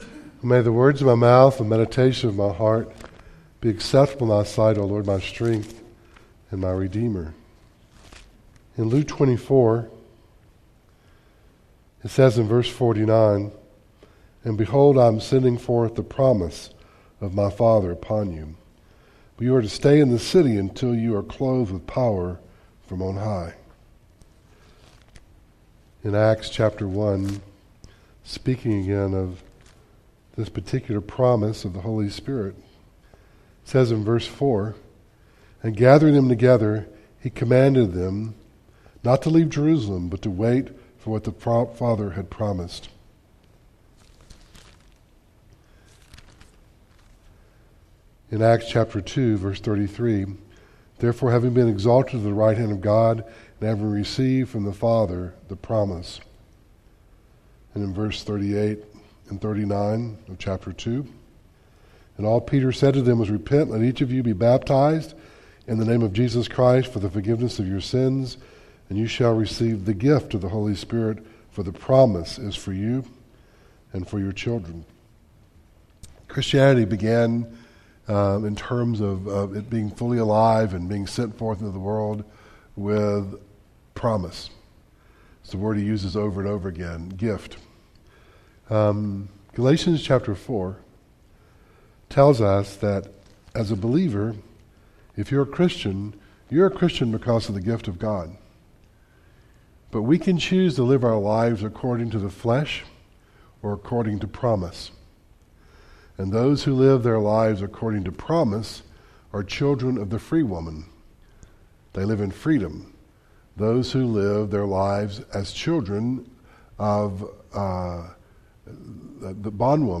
Galatians 4:21-31 Service Type: Devotional